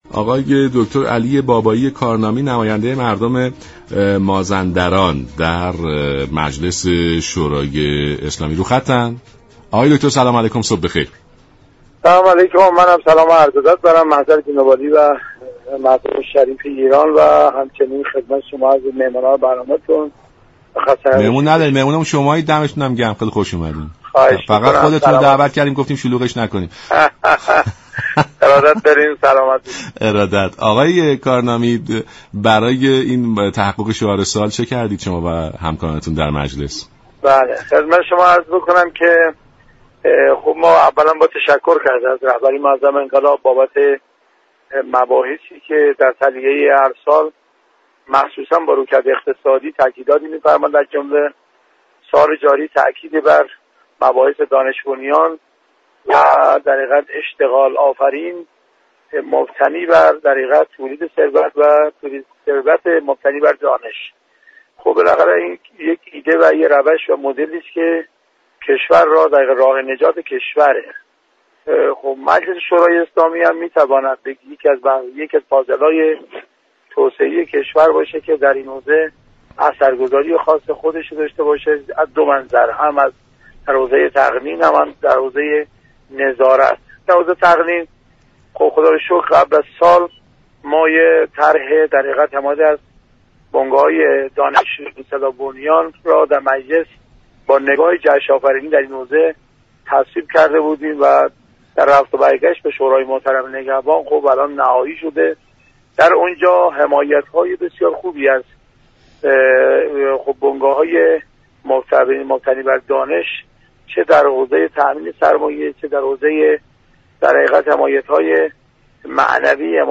دریافت فایل شخصیت مهم خبری: علی بابایی كارنامی نماینده مردم مازندران در مجلس شورای اسلامی تنظیم كننده